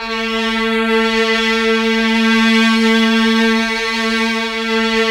Index of /90_sSampleCDs/Roland LCDP09 Keys of the 60s and 70s 1/KEY_Chamberlin/STR_Chambrln Str